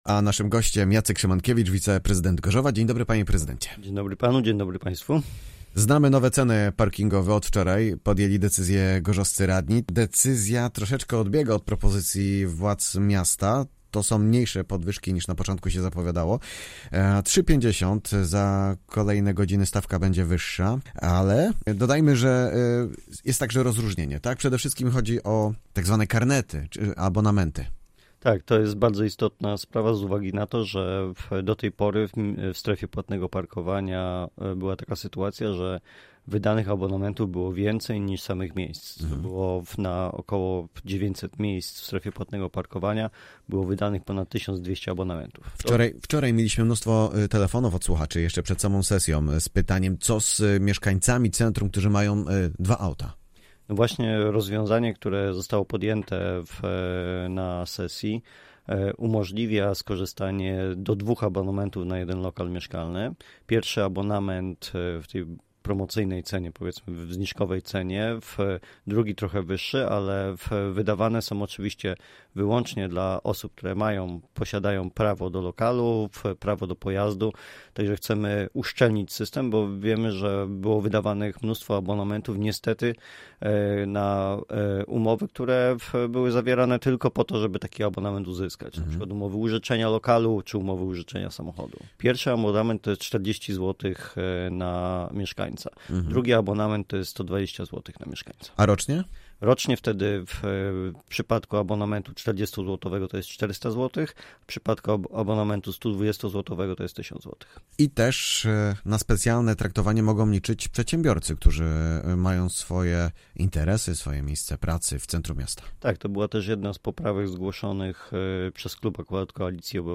Z wiceprezydentem Gorzowa